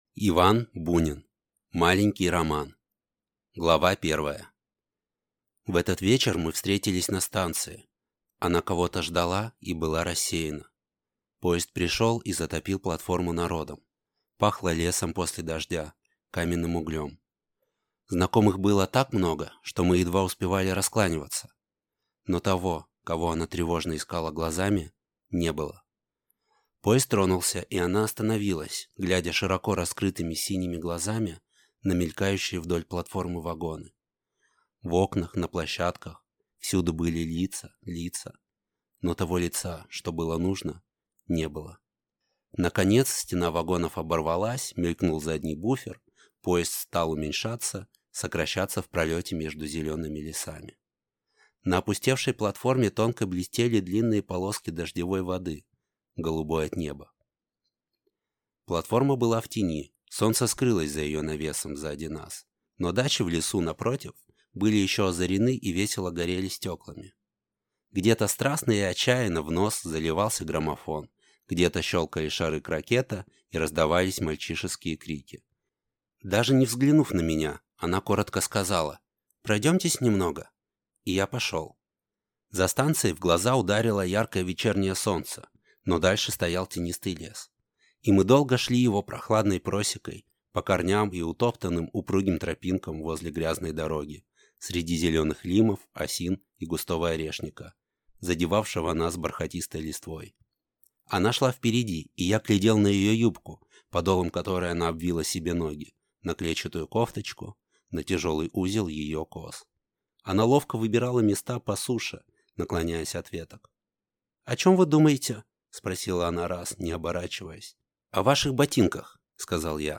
Аудиокнига Маленький роман | Библиотека аудиокниг